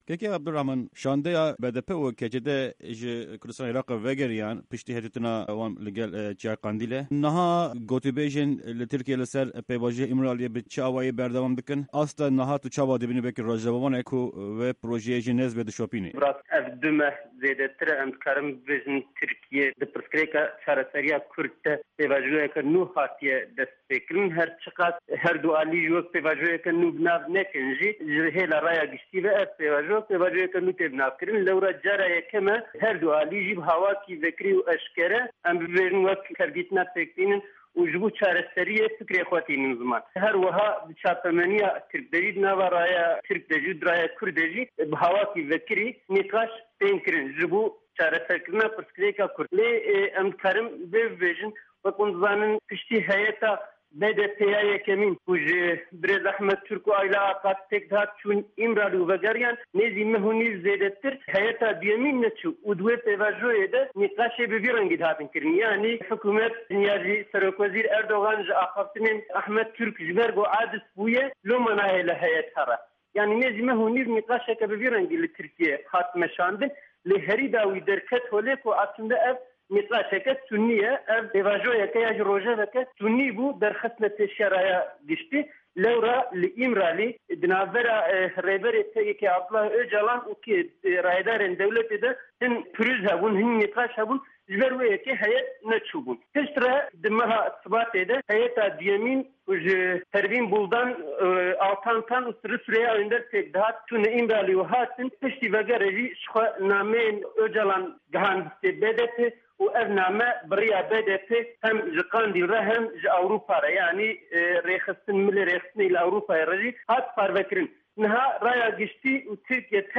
Di hevpeyvîna Pişka Kurdî ya Dengê Amerîka de nûçegîhanê Ajansa Nûçeyan a Dîcleyê